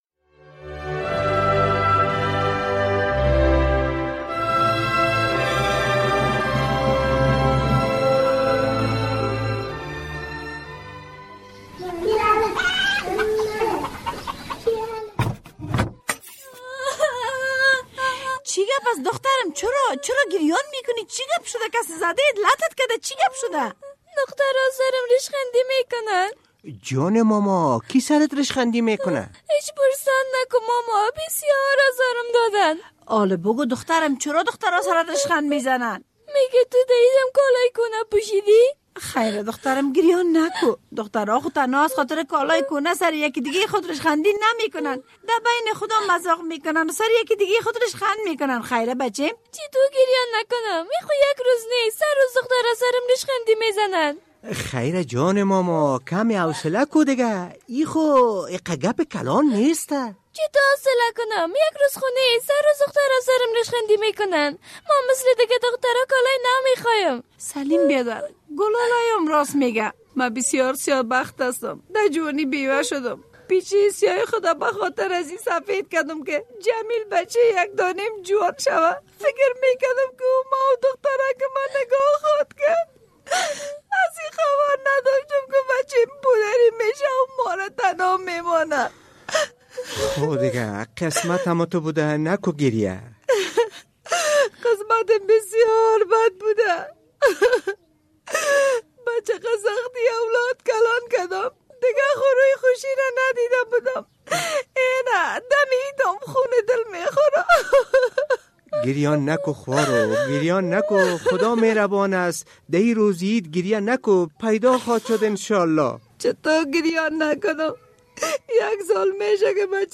درامه: دختران چرا به گلالی طعنه داده‌اند؟